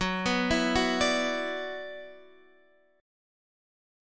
F# 7th Suspended 4th Sharp 5th